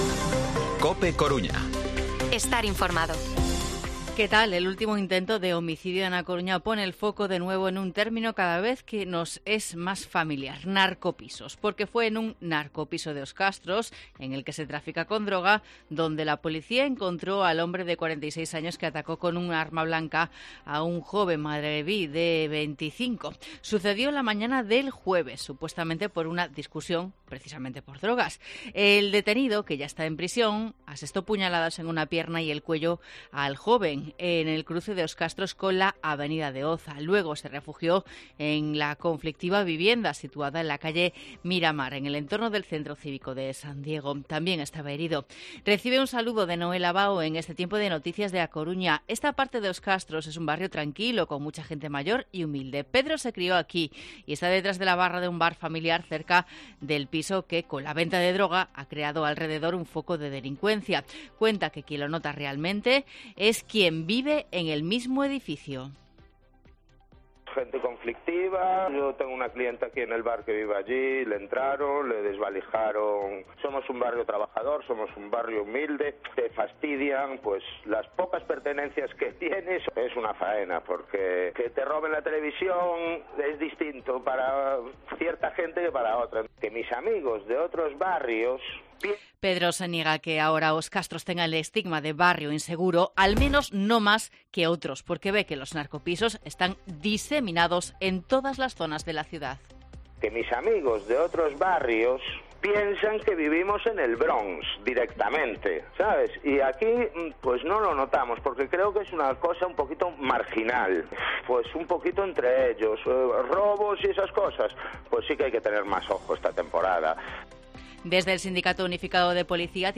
Informativo Mediodía COPE Coruña lunes, 10 de abril de 2023 14:20-14:30